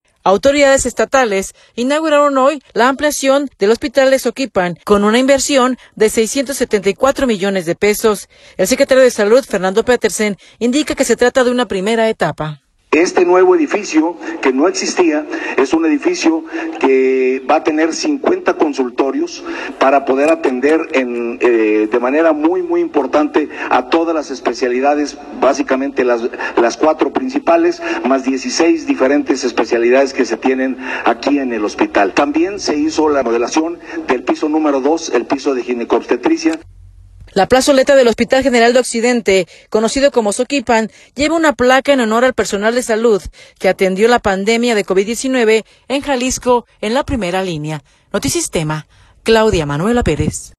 Autoridades estatales inauguraron hoy la ampliación del Hospital de Zoquipan con una inversión de 674 millones de pesos. El secretario de Salud, Fernando Petersen, indica que se trata de una primera etapa.